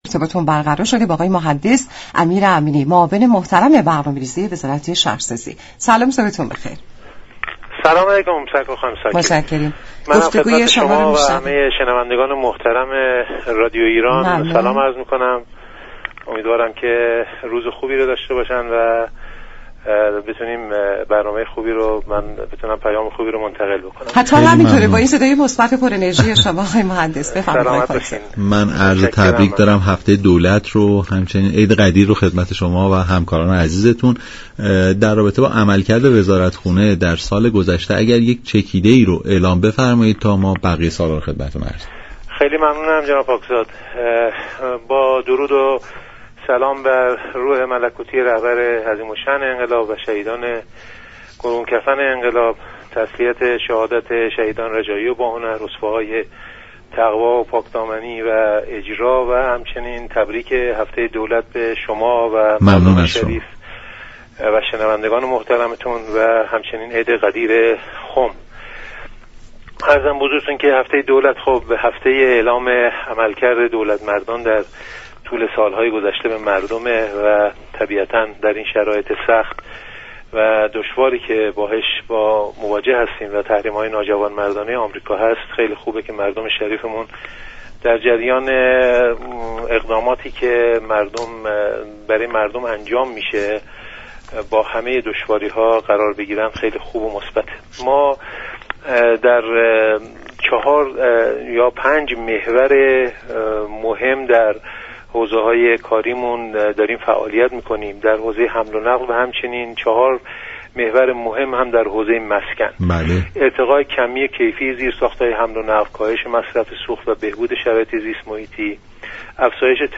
معاون برنامه ریزی وزارت راه و شهرسازی در گفت و گو با برنامه «نمودار» گفت